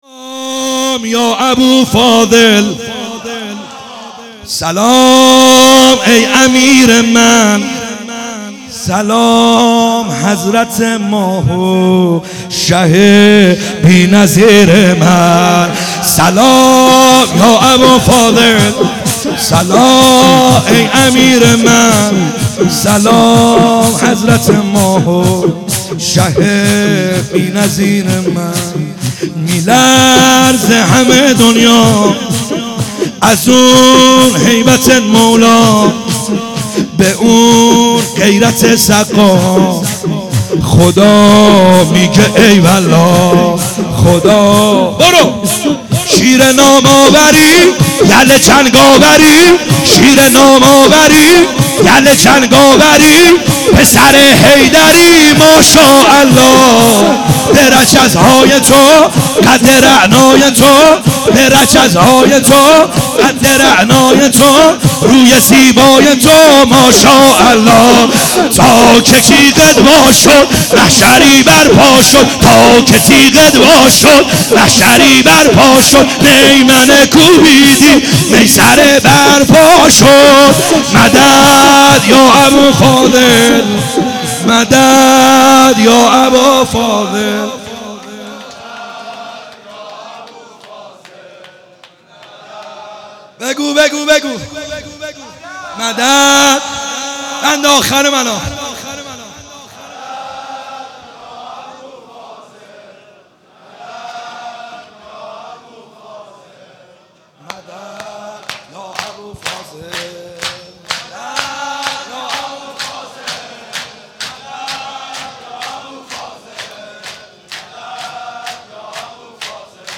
شور
شب بیست و یکم رمضان